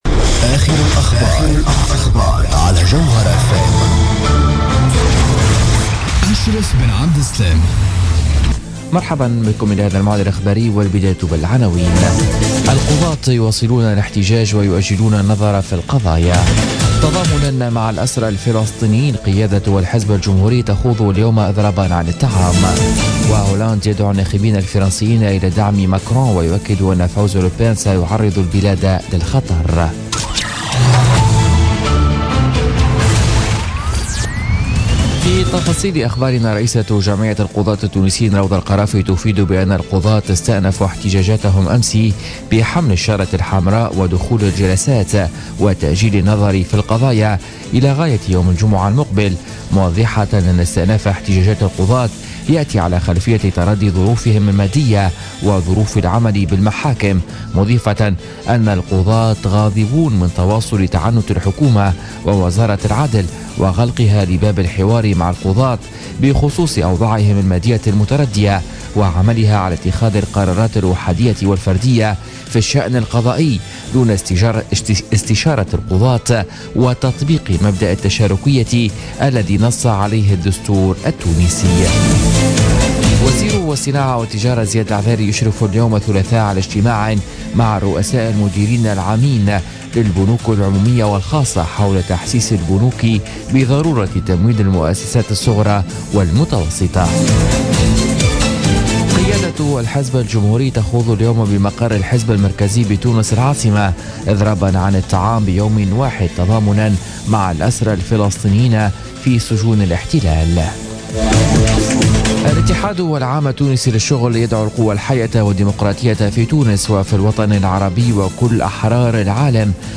نشرة أخبار منتصف الليل ليوم الثلاثاء 25 أفريل 2017